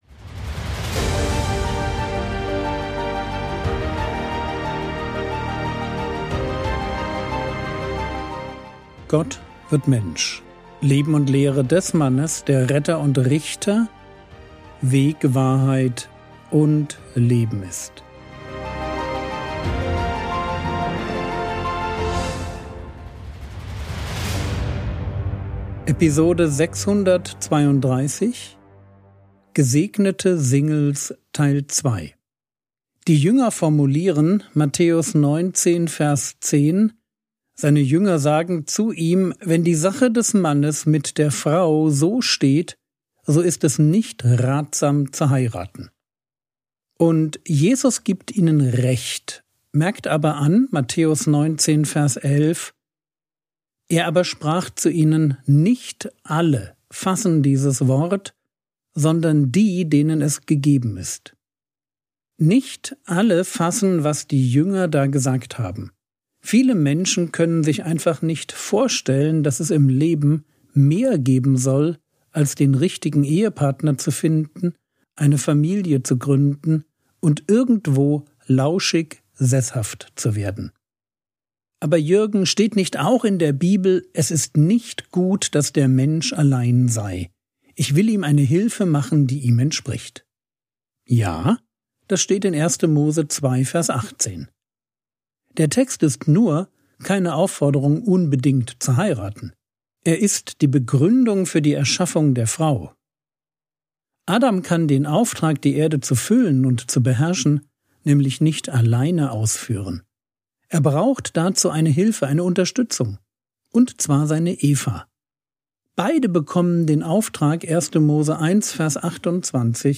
Episode 632 | Jesu Leben und Lehre ~ Frogwords Mini-Predigt Podcast